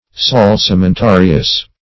Salsamentarious \Sal`sa*men*ta"ri*ous\